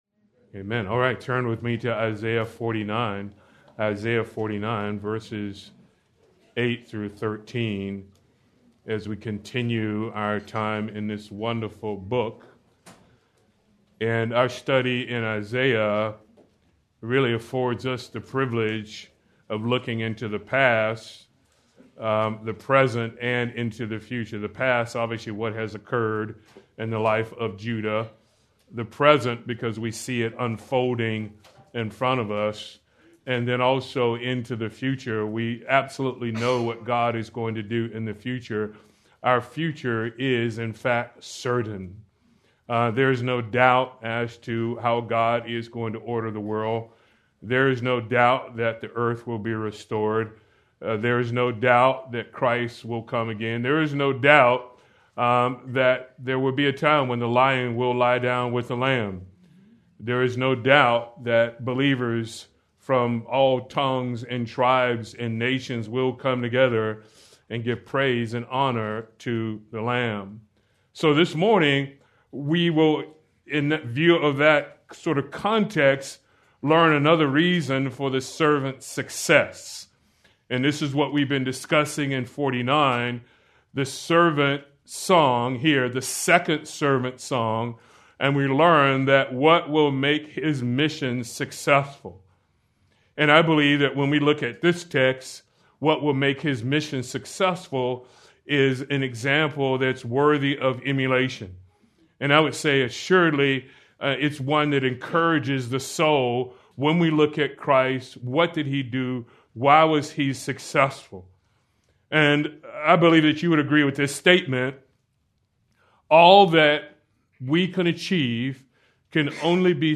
March 1, 2026 -Sermon